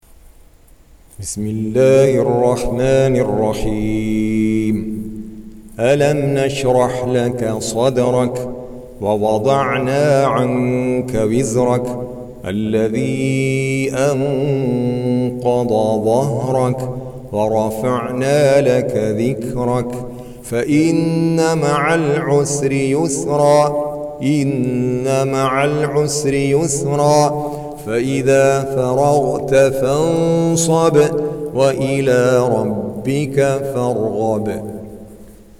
تكرار القرآن Repeat Quran 94. Surah Ash-Sharh سورة الشرح Audio Quran Tarteel Recitation
Surah Sequence تتابع السورة Download Surah حمّل السورة Reciting Murattalah Audio for 94. Surah Ash-Sharh سورة الشرح N.B *Surah Includes Al-Basmalah Reciters Sequents تتابع التلاوات Reciters Repeats تكرار التلاوات